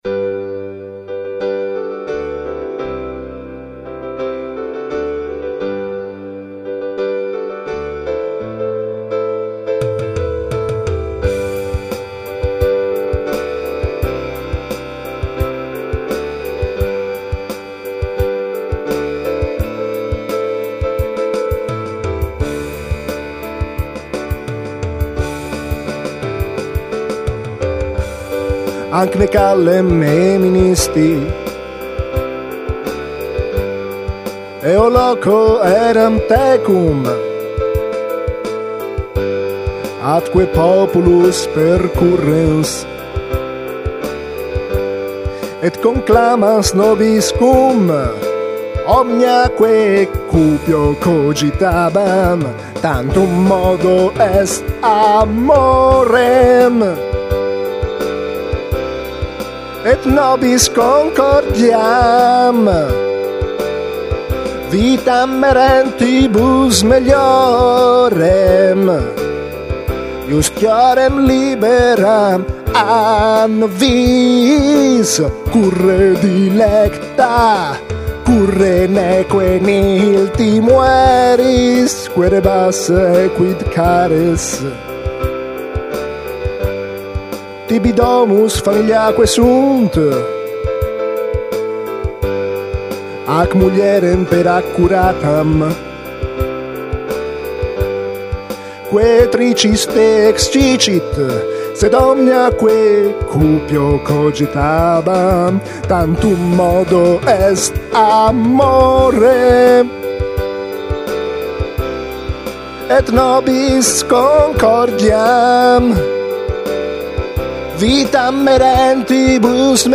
sembra uno che canta al contrario!